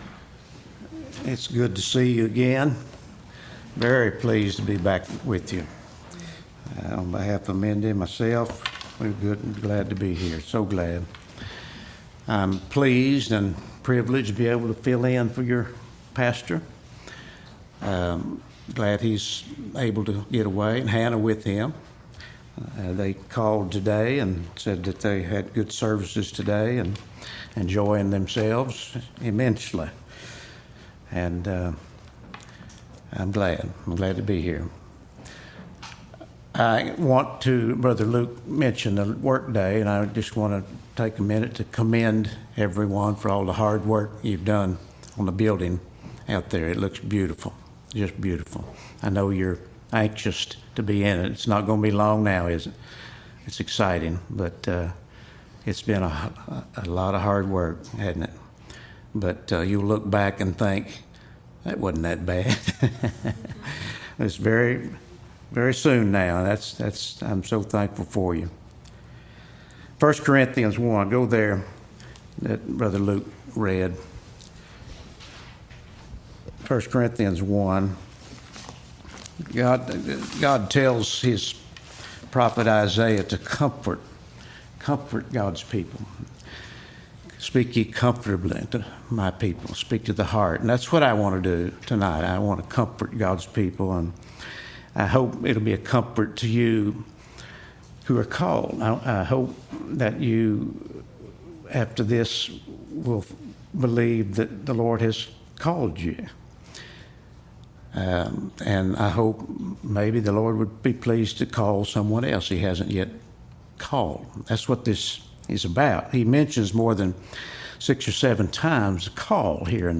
The Call, Called & Calling | SermonAudio Broadcaster is Live View the Live Stream Share this sermon Disabled by adblocker Copy URL Copied!